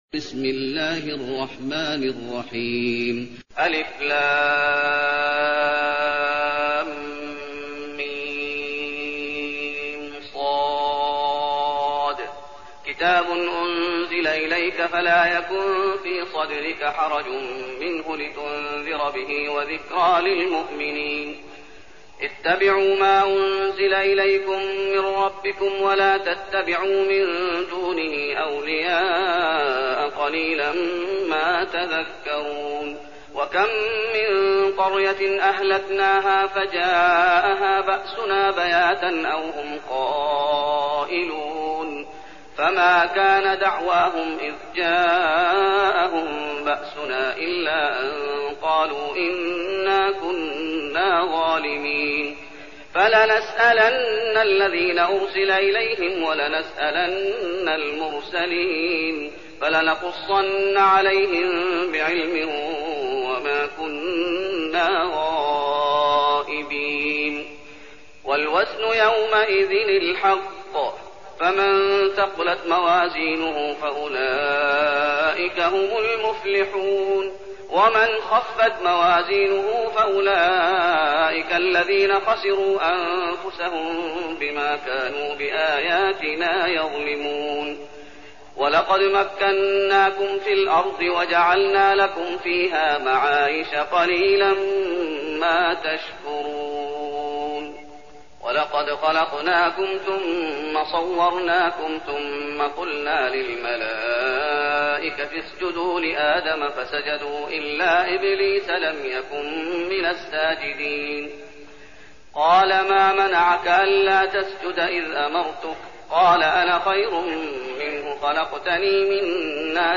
المكان: المسجد النبوي الأعراف